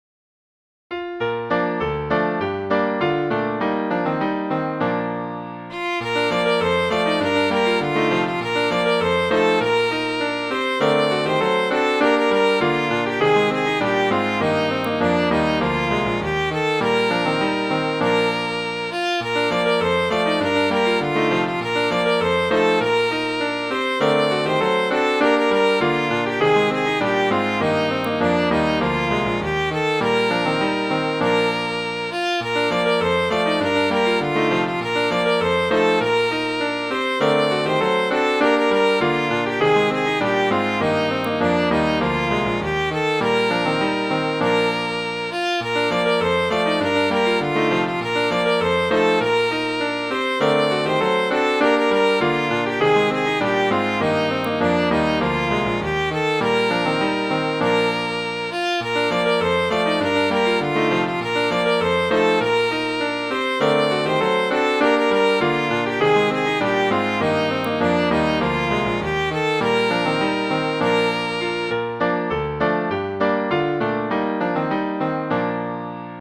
Midi File, Lyrics and Information to Poor Old Horse?